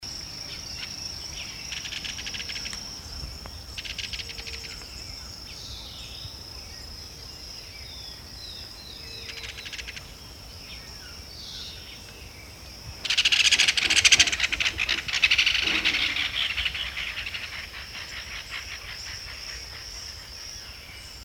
Martín Pescador – Universidad Católica de Temuco
Martin-pescador-Megaceryle-torquata.mp3